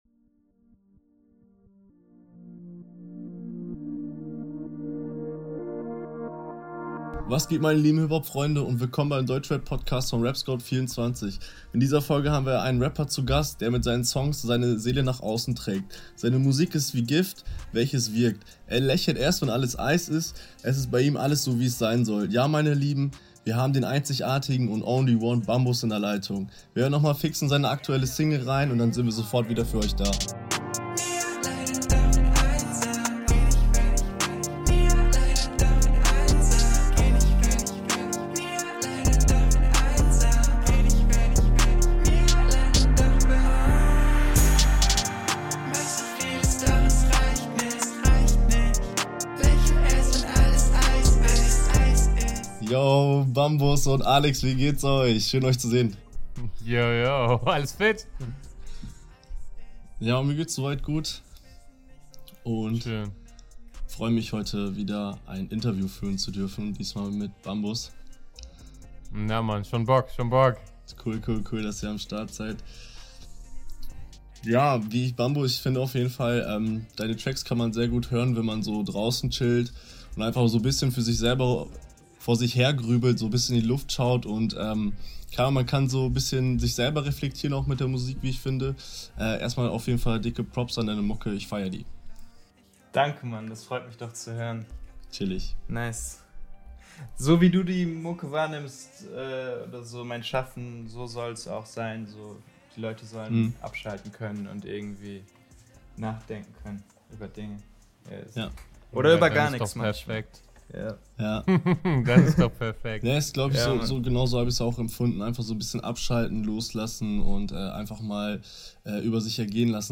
#17 Interview